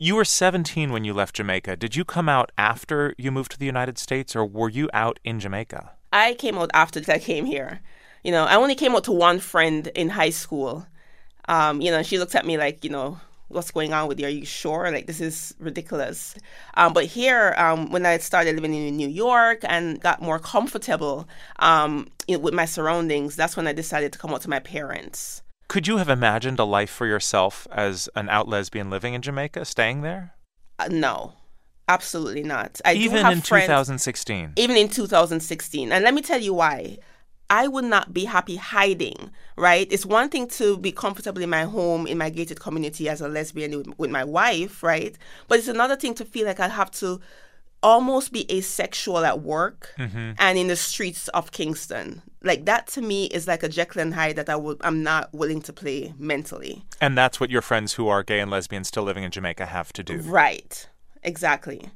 An interview with Nicole Dennis-Benn conducted by Ari Shapiro of the National Public Radio